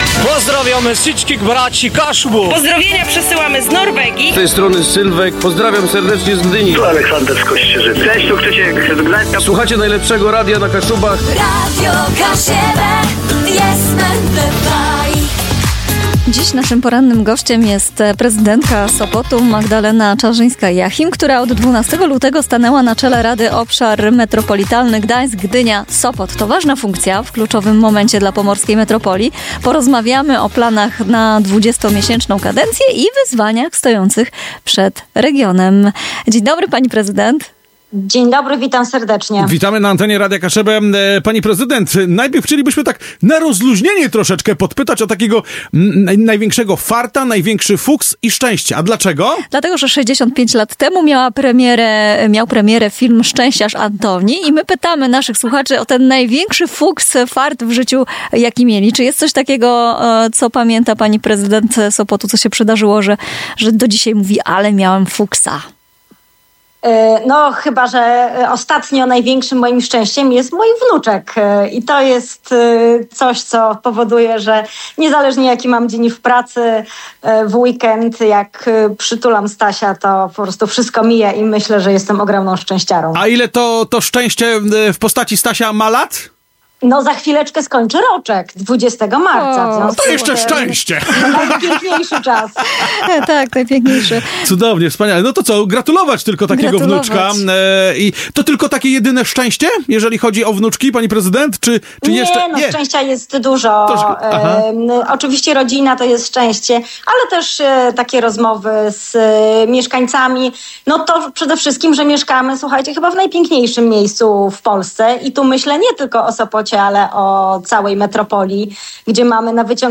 Gościem porannej audycji w Radiu Kaszëbë była Magdalena Czarzyńska-Jachim, Prezydent Sopotu, która od 12 lutego 2024 roku pełni funkcję przewodniczącej Rady Obszaru Metropolitalnego Gdańsk-Gdynia-Sopot (OMGGS). W szczerej rozmowie przedstawiła priorytety swojej 20-miesięcznej kadencji, kładąc szczególny nacisk na integrację regionu, rozwój transportu oraz realne wsparcie dla wszystkich 61 gmin tworzących metropolię.